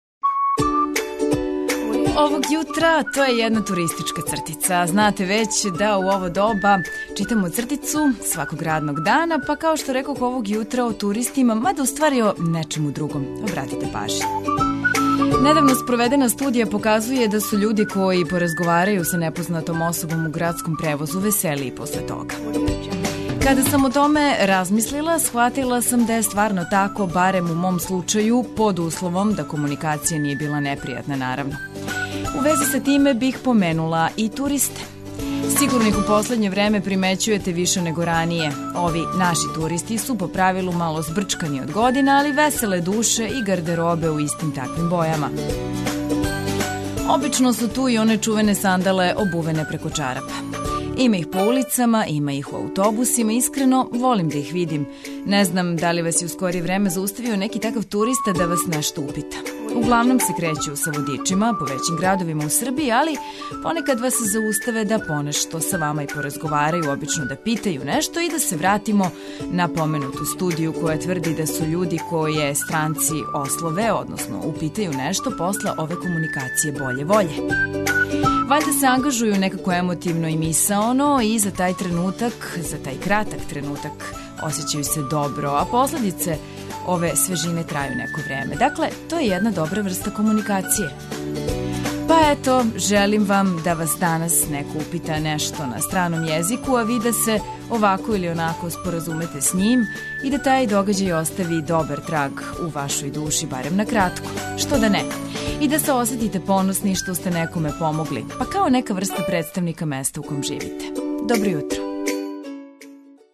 У томе ће помоћи музика за разбуђивање као и различите информације које ваља чути изјутра. Међу њима су, свакако, сервисне информације, стање на путевима, прогноза времена и нове вести.